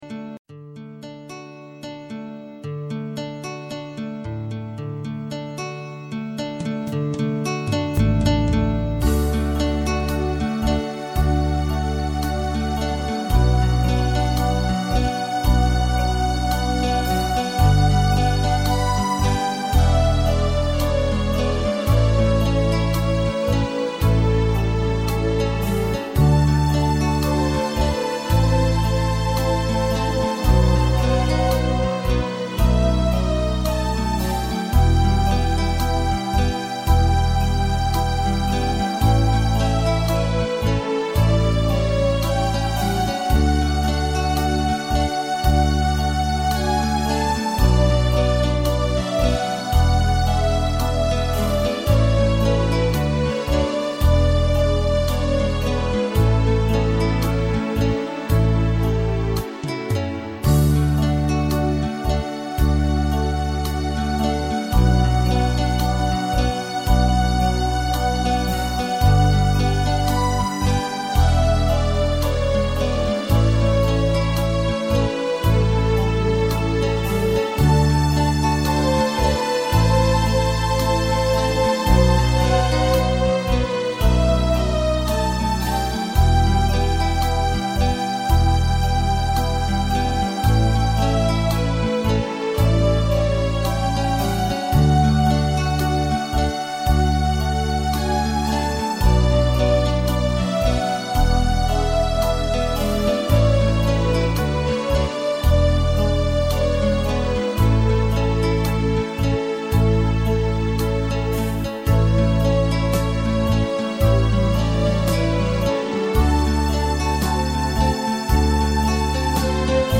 Keyboard und Synthesizer-Klassik
Keyboard und Synthesizer